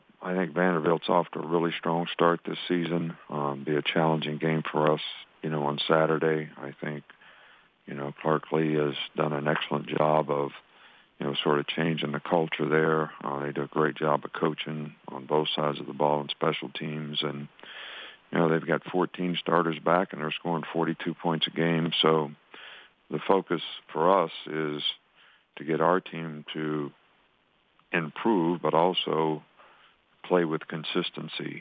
In media availability, Alabama’s head coach praised Clark Lea and the progress Vanderbilt has shown in his second year.
saban-on-vandy.wav